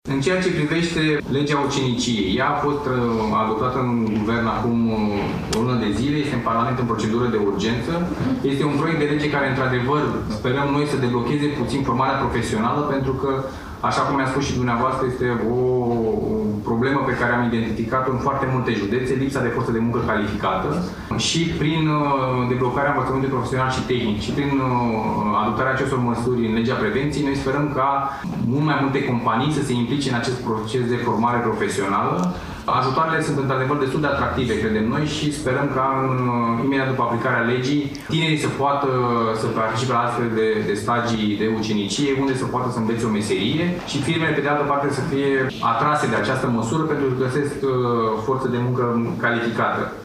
Ministrul Consultării Publice şi Dialogului Social, Gabriel Petrea, a declarat astăzi, la Roman, că legea prevenţiei, adoptată în ultima şedinţă a Guvernului, nu este un act normativ cu privire la drepturi şi libertăţi, ci este o lege care sprijină angajaţii şi angajatorii.